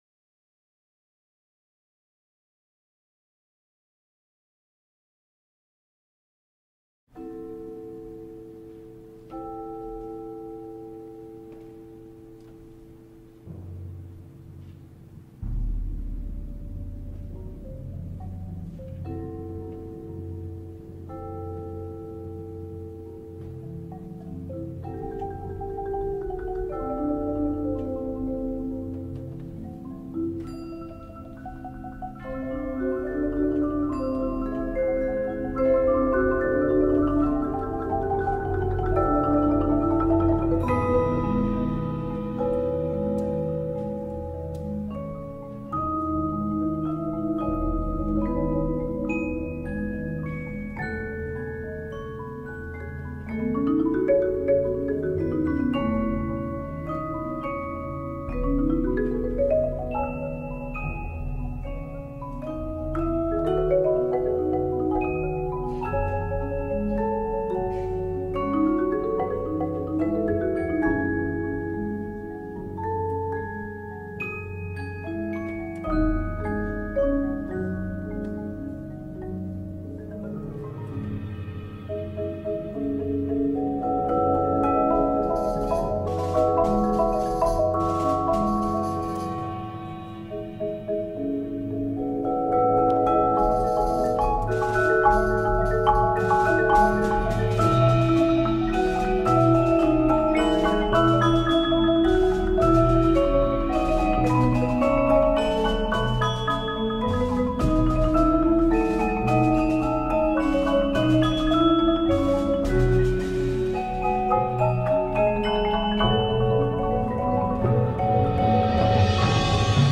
Genre: Percussion Ensemble
Glockenspiel/Crotales
Xylophone/Chimes
Vibraphone 1–2
Timpani